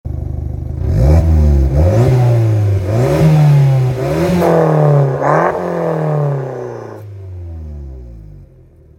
Der G-TECH Klappen-Mittelschalldämpfer reguliert die Abgasmenge und den Klang über eine Stauklappe für ein sportliches und dennoch alltagstaugliches Sounderlebnis.
Audio: GT550-ST mit geschlossener Klappe
G_TECH_GT550-ST_Klappe-geschlossen.mp3